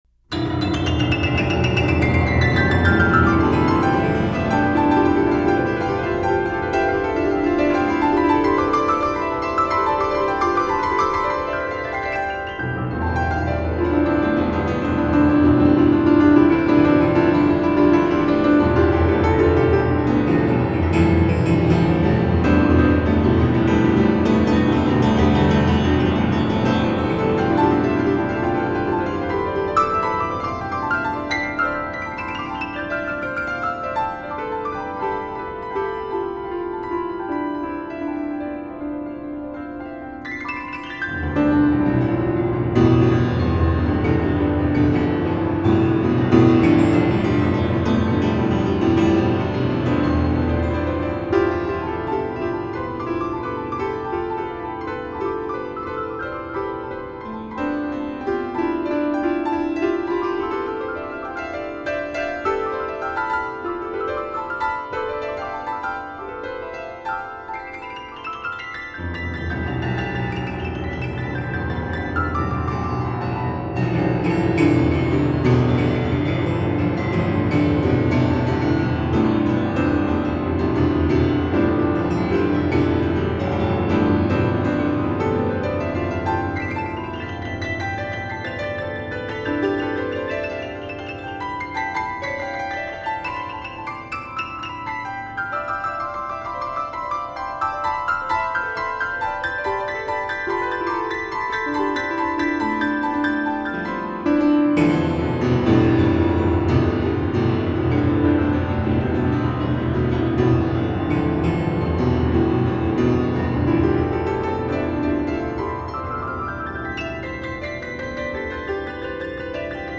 Piano Page created